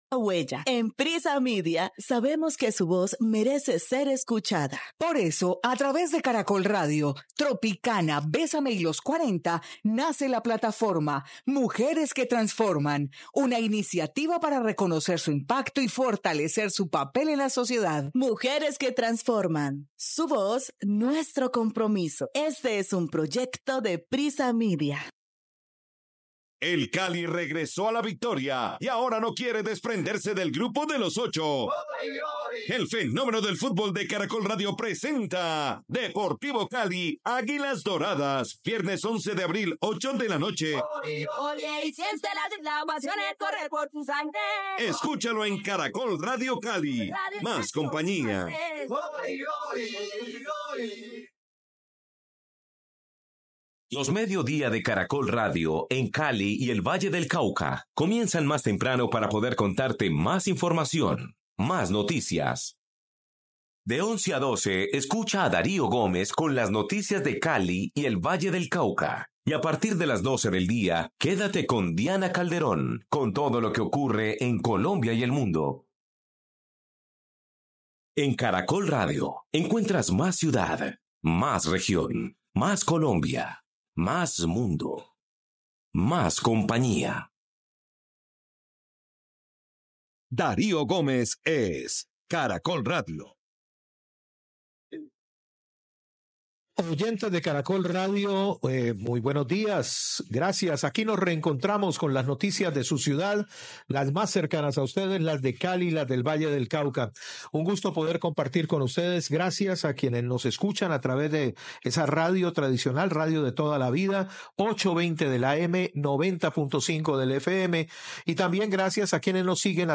El conversatorio del 11 de abril reunió a tres destacadas líderes que, desde distintos ámbitos, han dedicado su vida al servicio y transformación de sus comunidades.